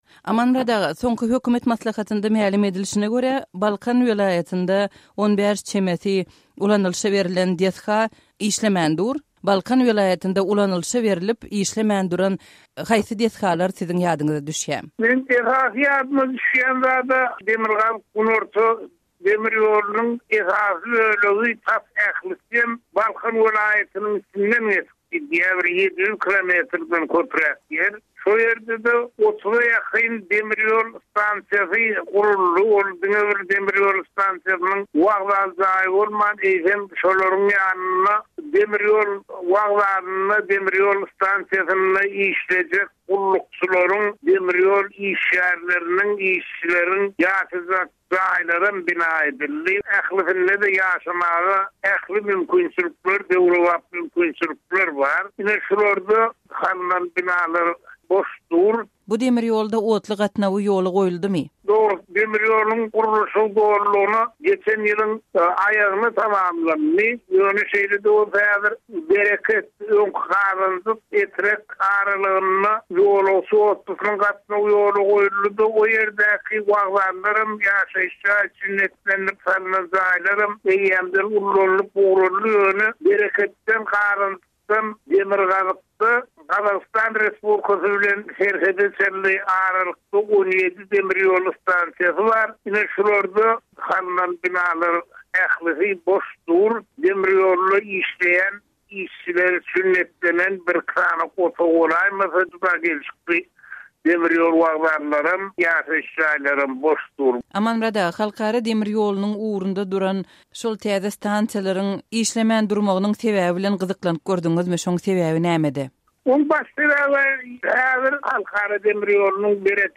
söhbetdeşligi